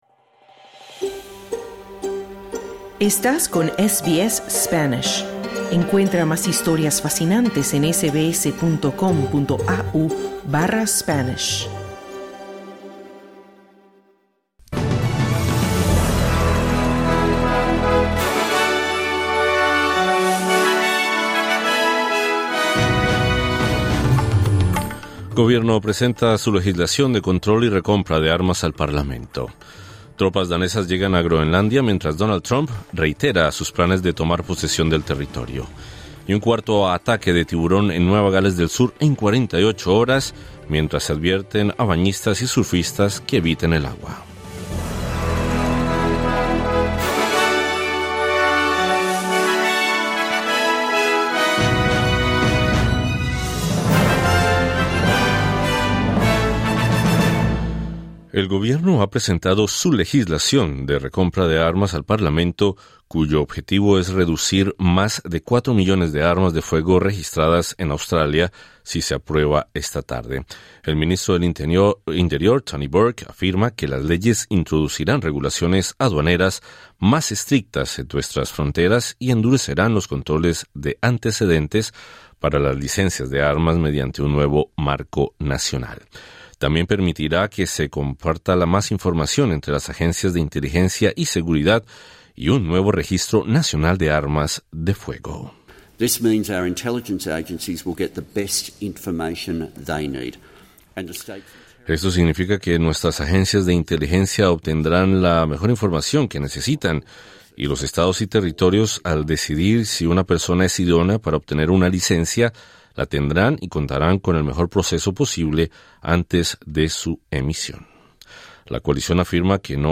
El ministro del Interior, Tony Burke, afirma que las leyes introducirán regulaciones aduaneras más estrictas en nuestras fronteras y controles de antecedentes para las licencias de armas. Escucha el resumen informativo de este martes 20 de enero 2026.